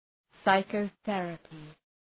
Προφορά
{,saıkəʋ’ɵerəpı}